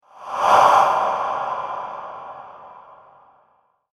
Whoosh Breath Sound Effect
A crisp and realistic spooky swoosh transition sound effect that captures the chilling rush of ghostly air in motion.
Scary sounds.
Whoosh-breath-sound-effect.mp3